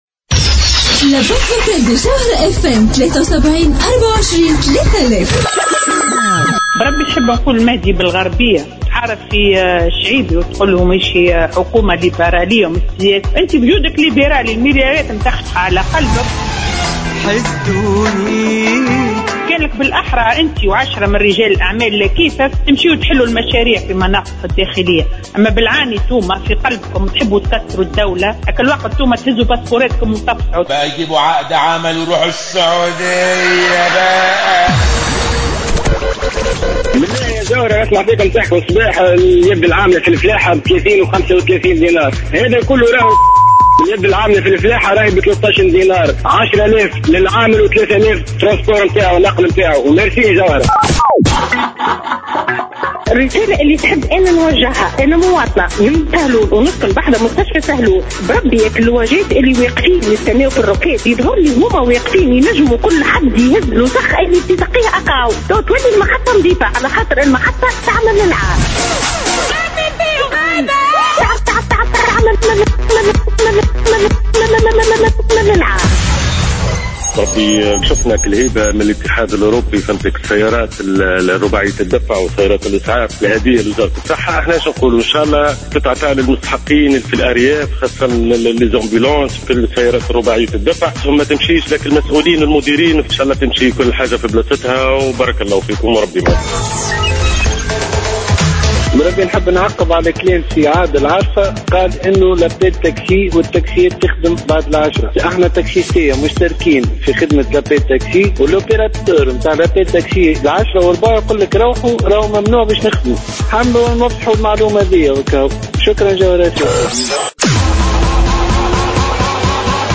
مستمعون يوجّهون رسالتين للمهدي بن غربية و الهاشمي الحامدي